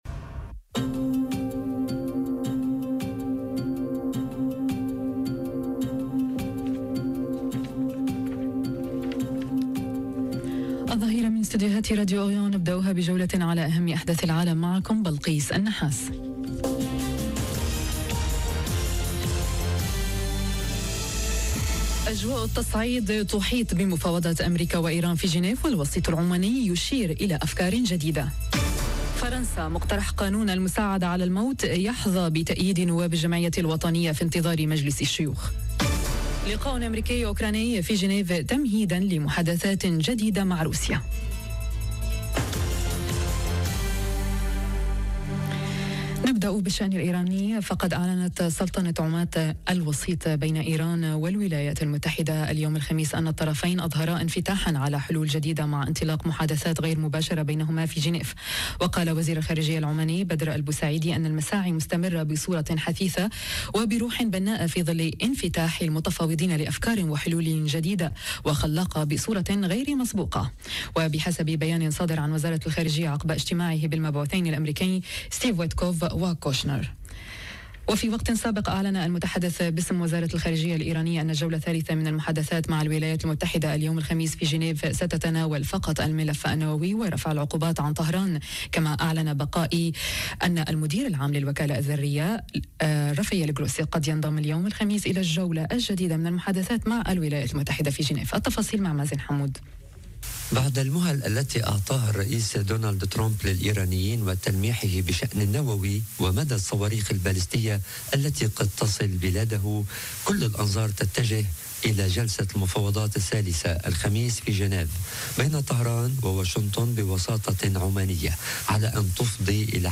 نشرة الظهيرة ..